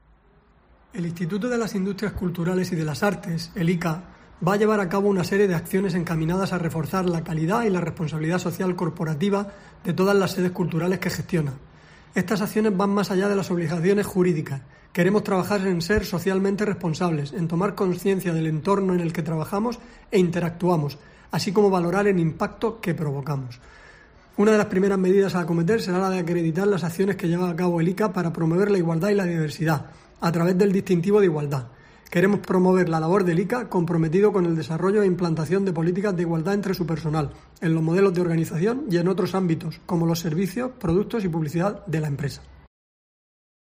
José Ramón Palazón, director general del ICA